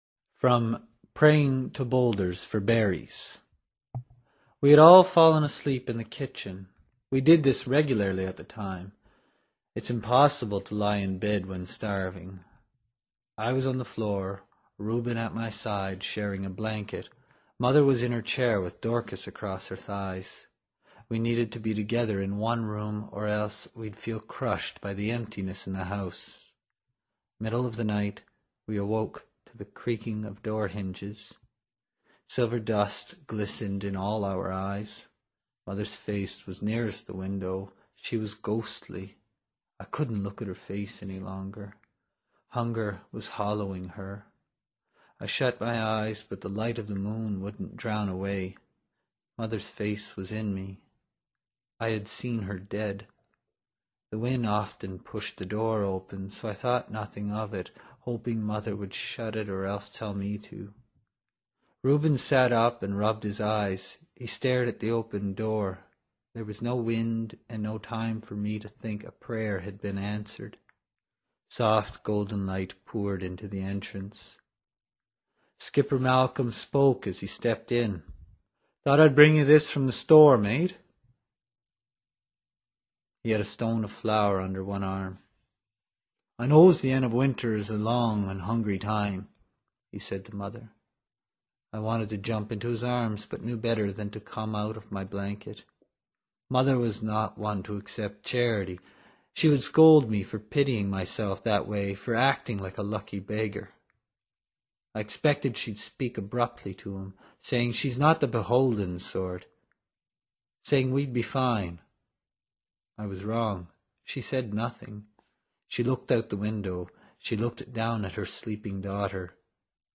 reads an excerpt